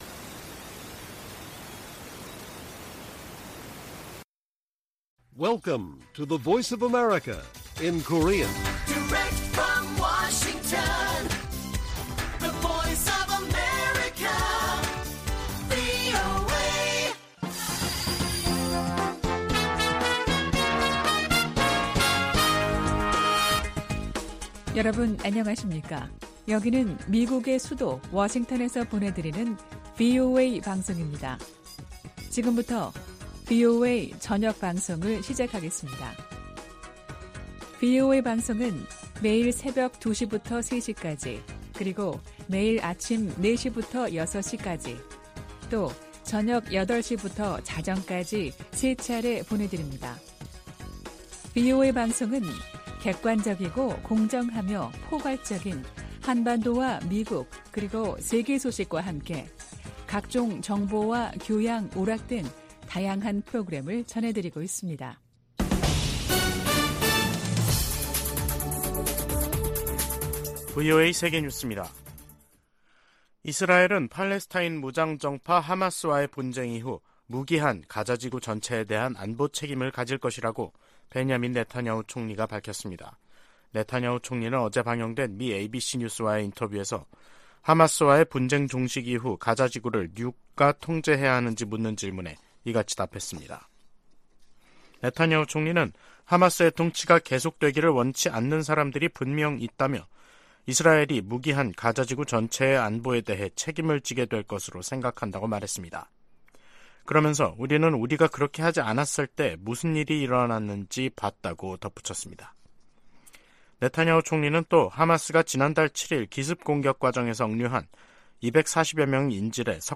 VOA 한국어 간판 뉴스 프로그램 '뉴스 투데이', 2023년 11월 7일 1부 방송입니다. 미 국방부는 북한이 미한일 군사 협력에 연일 비난과 위협을 가하는데 대해, 이들 정부와 계속 협력해 나가겠다는 뜻을 거듭 밝혔습니다. 유럽과 중동의 두 개 전쟁이 한반도를 비롯한 아시아 안보도 위협하고 있다고 미 상원의원들이 밝혔습니다. 한국과 일본이 이달말 한중일 외교장관회담에서 북러 밀착에 대한 중국의 불안을 공략해야 한다고 미 전문가들이 말했습니다.